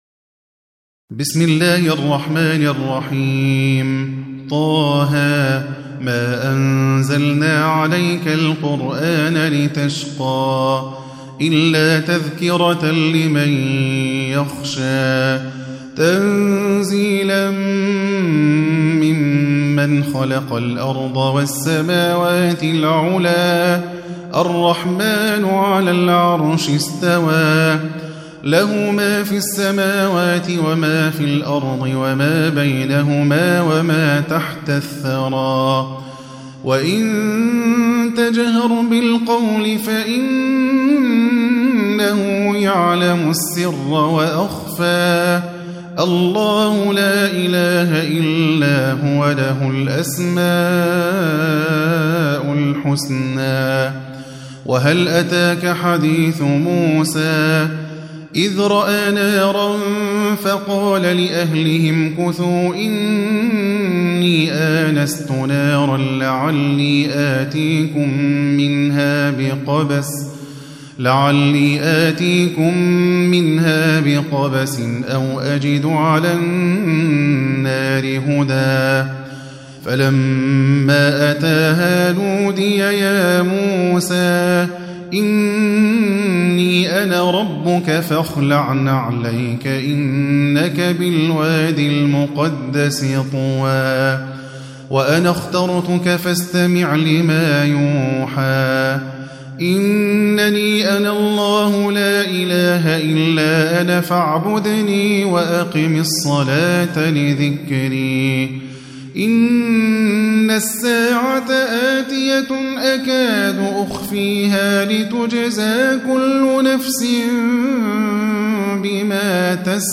20. Surah T�H�. سورة طه Audio Quran Tarteel Recitation
حفص عن عاصم Hafs for Assem